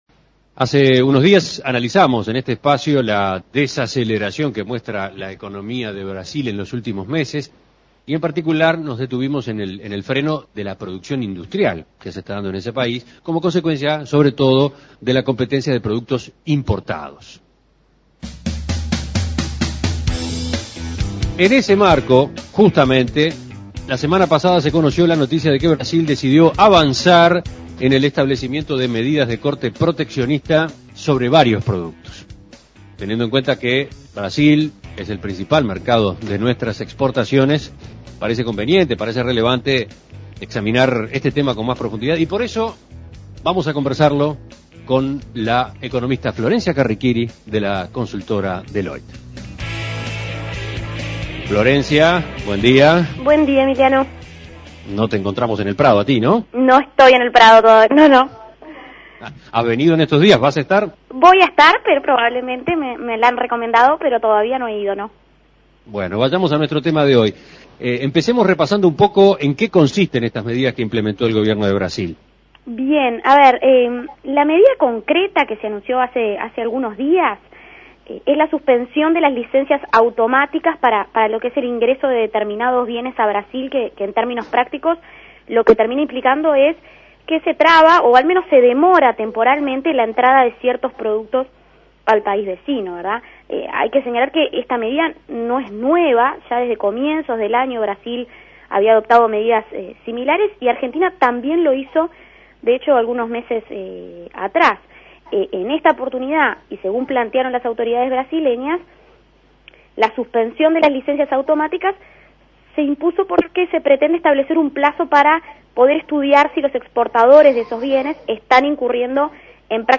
Análisis Económico Brasil anunció nuevas medidas para proteger la producción local: ¿en qué consisten esas disposiciones y cómo pueden afectar a las exportaciones uruguayas?